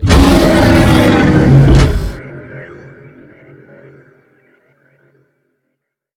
combat / ENEMY / droid / bigatt3.wav